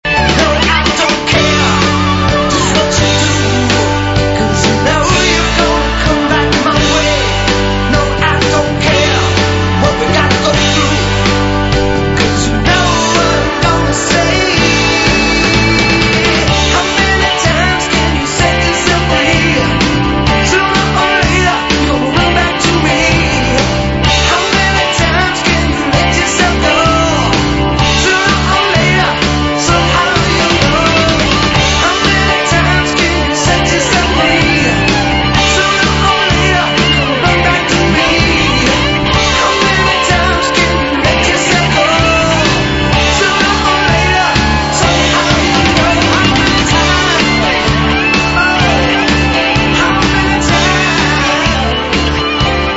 最初はギターソロを前面に押し出したストレートなロック・テイストだが
メロディアスなＡＯＲ